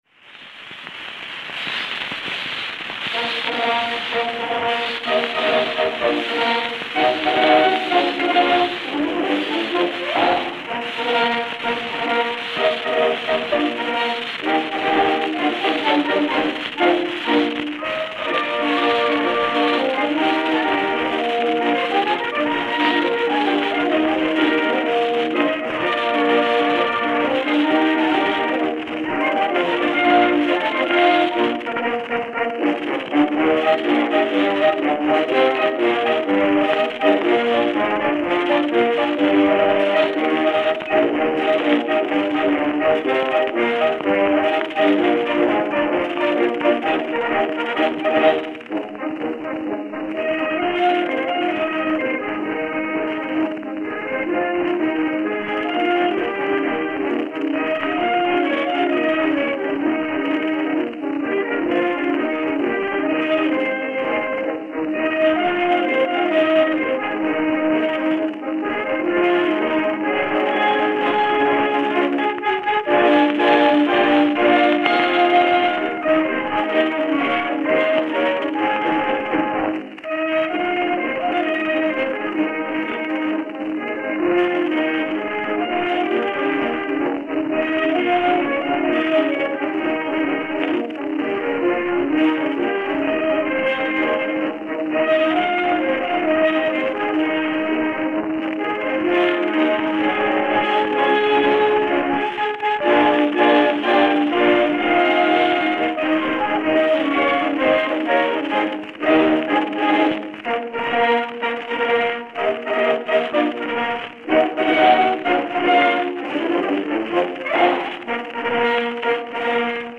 Camden, New Jersey Camden, New Jersey